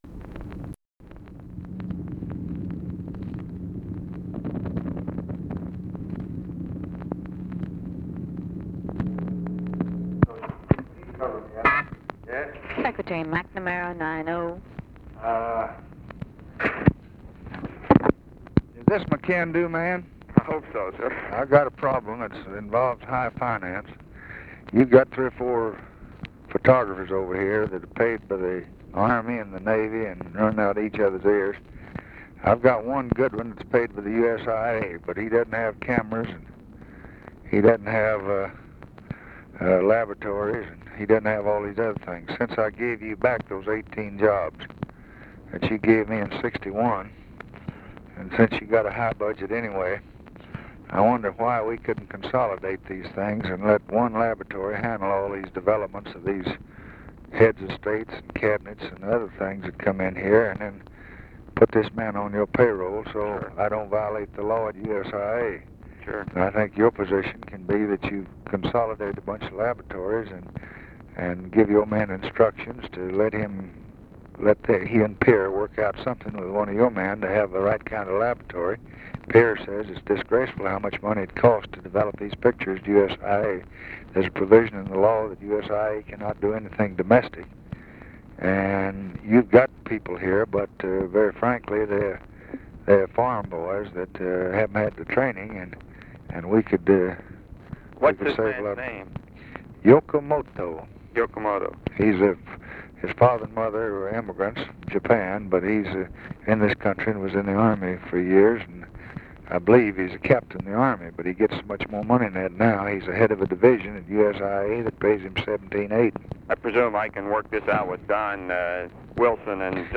Conversation with ROBERT MCNAMARA, January 6, 1964
Secret White House Tapes